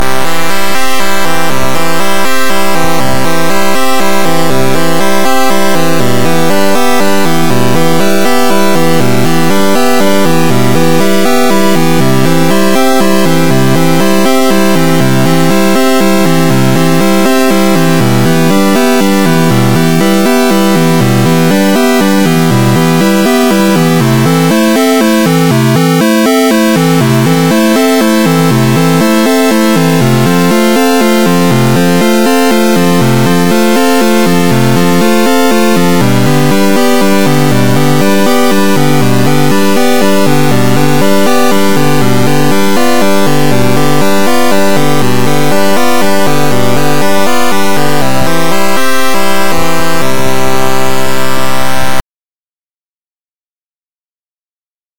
09-ARP-TRIPLET-PW3-01.mp3